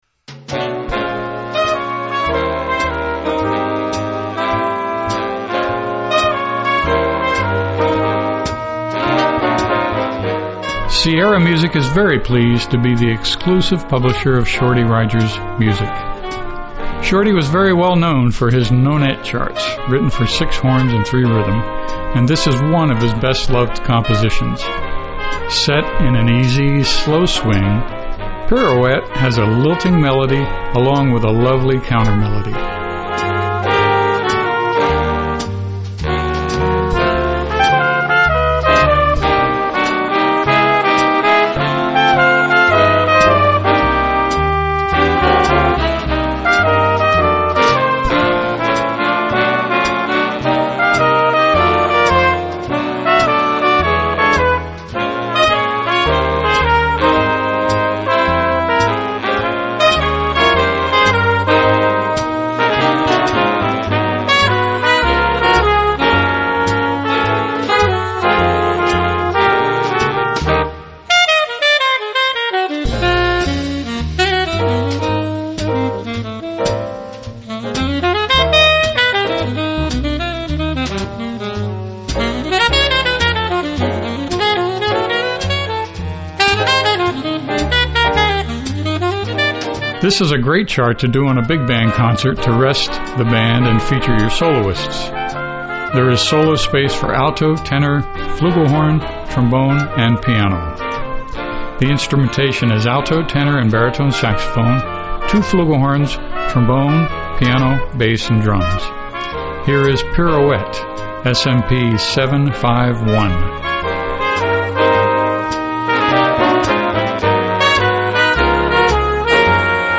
Voicing: Little Big Band